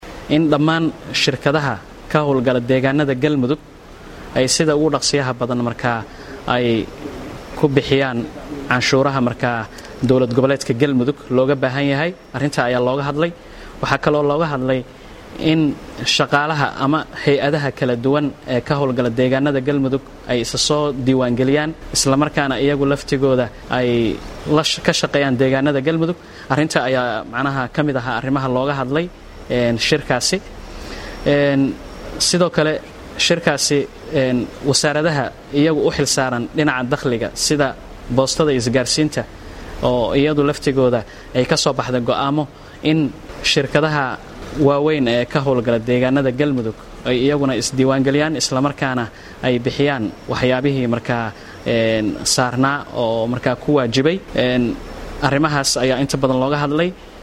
Kulanka kadib qodobadii leysla gaaray ayaa waxaa Warbaahinta u Akhriyay Wasiir ku xigeenka Wasaarada Warfaafinta Galmudug Cbdiraxmaan Jaamac Afrax.